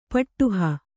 ♪ paṭṭuha